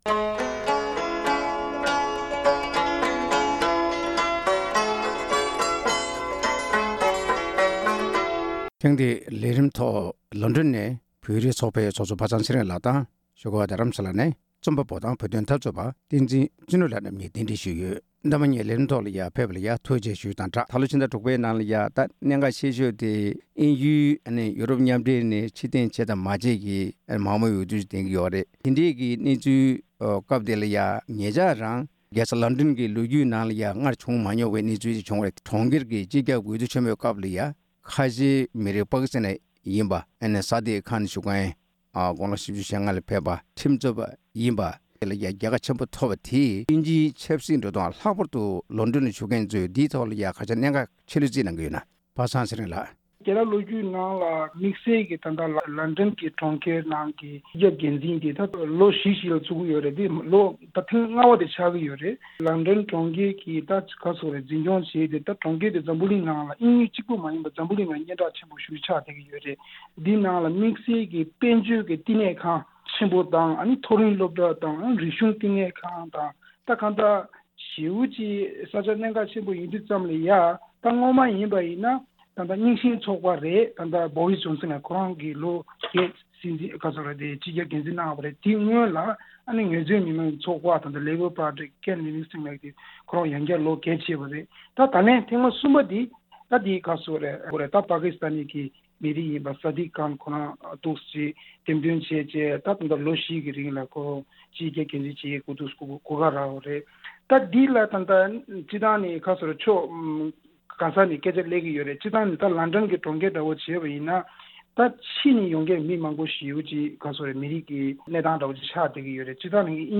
དབར་གླེང་མོལ་ཞུས་པ་ཞིག་གསན་གནང་གི་རེད༎